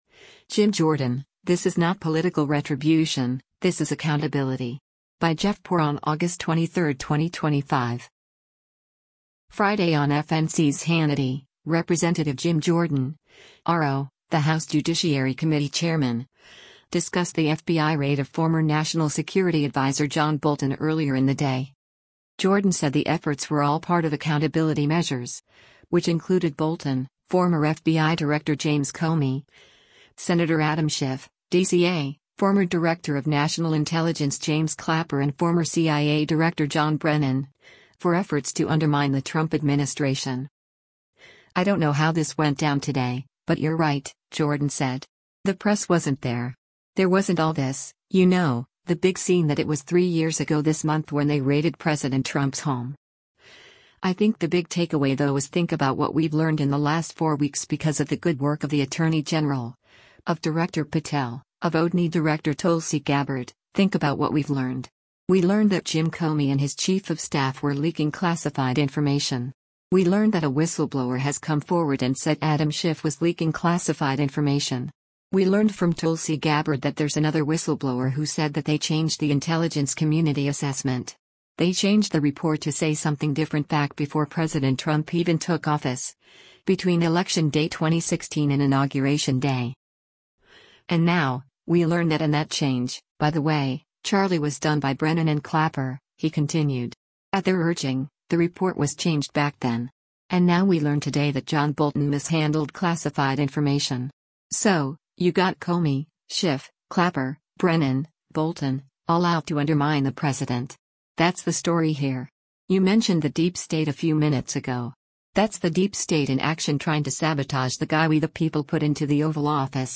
Friday on FNC’s “Hannity,” Rep. Jim Jordan (R-OH), the House Judiciary Committee chairman, discussed the FBI raid of former National Security Advisor John Bolton earlier in the day.